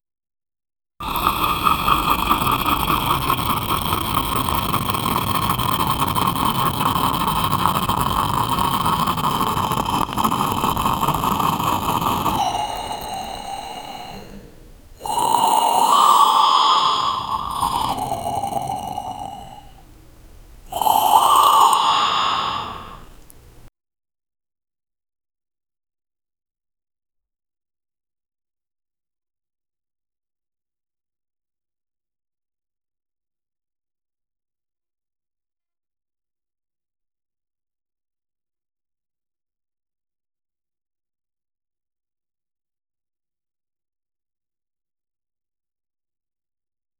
Witch brewing a potion, lots of pops, cute and ambient, sweet witch humming
witch-brewing-a-potion-lo-sbk4f5gp.wav